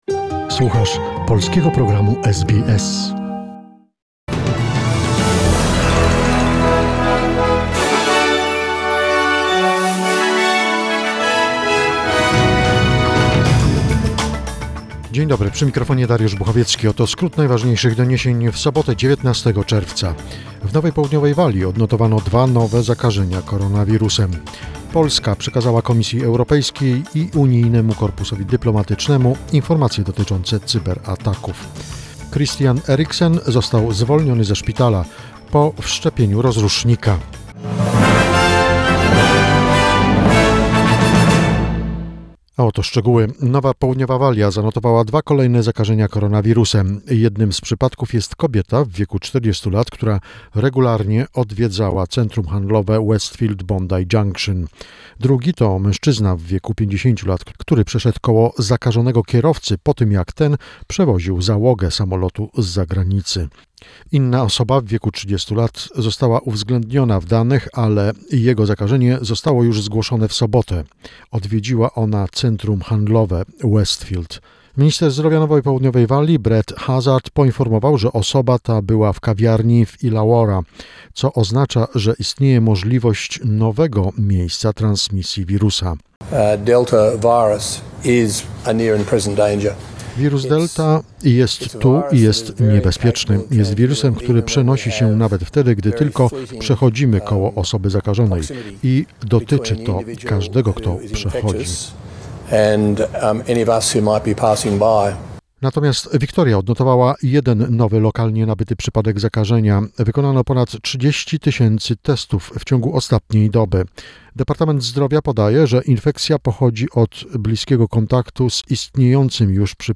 SBS News Flash in Polish, 19 June 2021